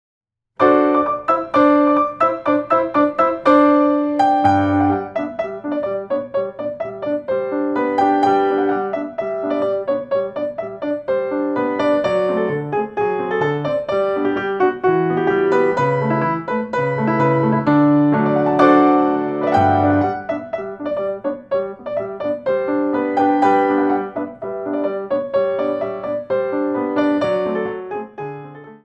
4 bar intro 2/4